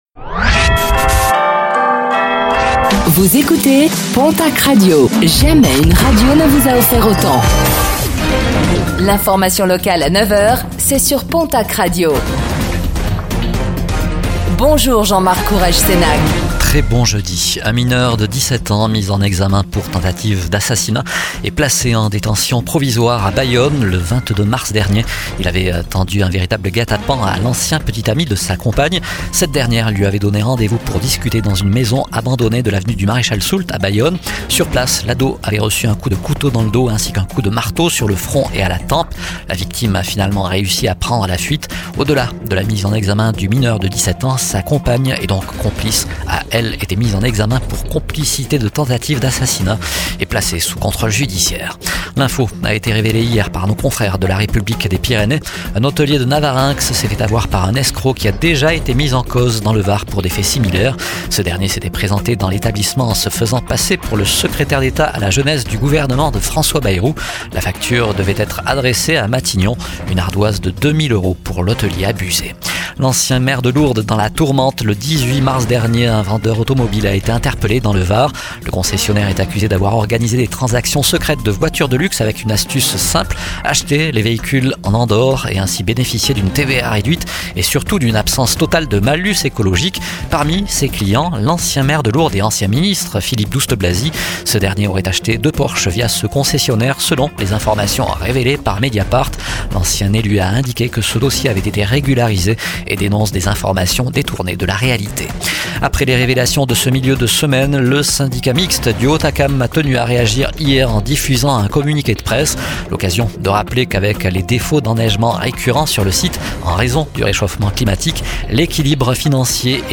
Infos | Jeudi 17 avril 2025